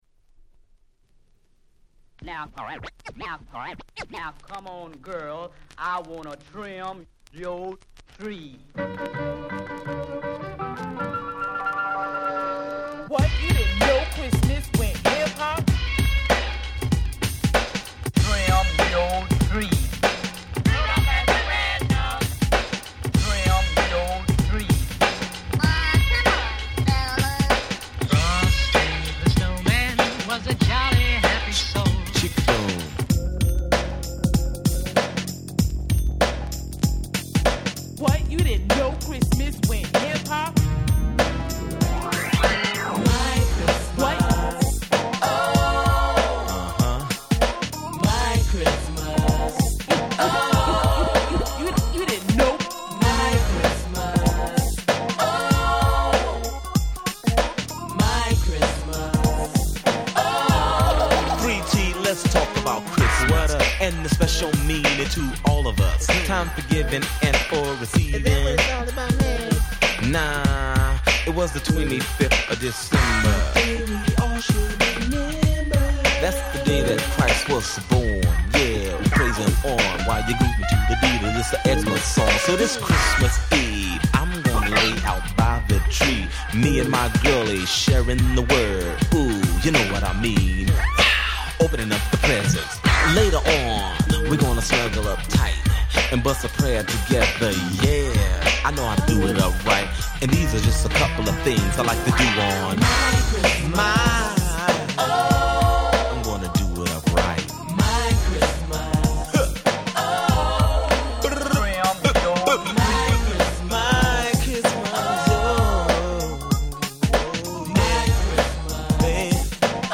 タイトル通り全曲クリスマスソング！！
音質もバッチリ！